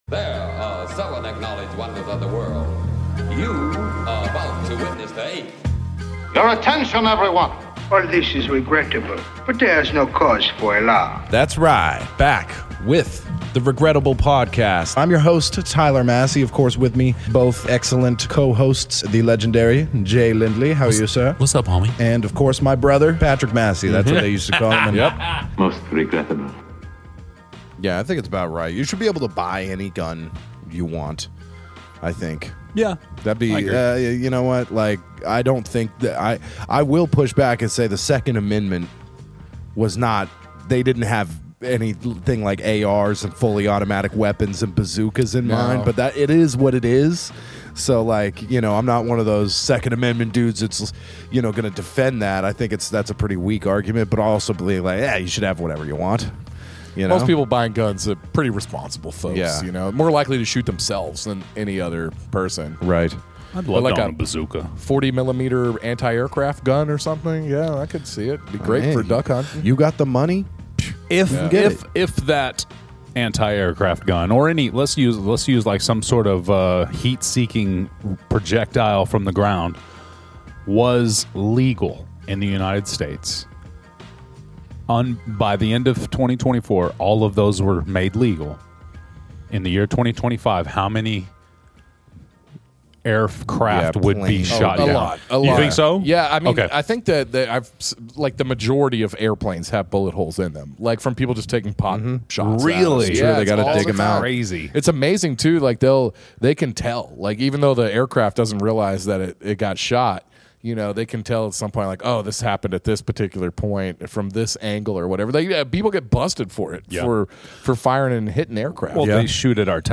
Disclaimer: Strong language, Sensitive subjects.